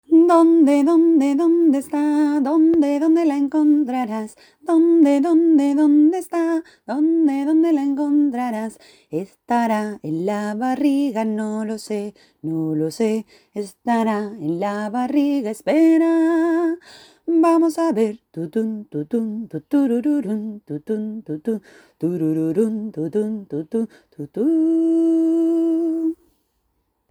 Mayor y triple.